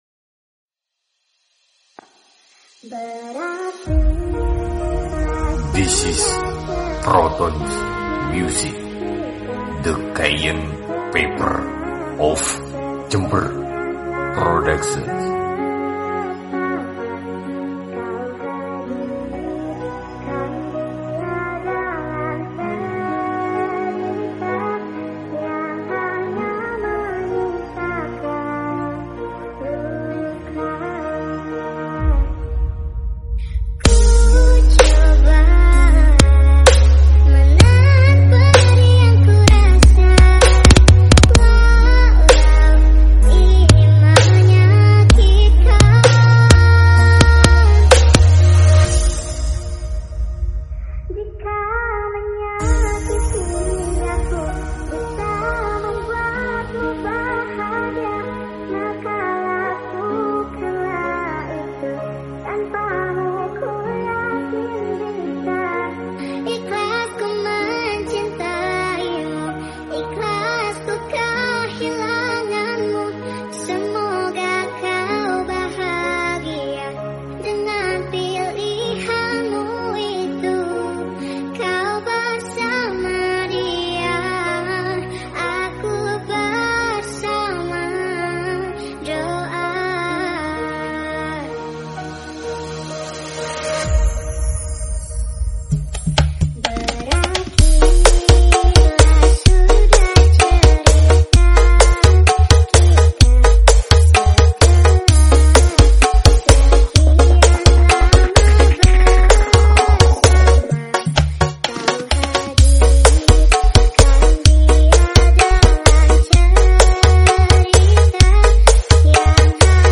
full battle bass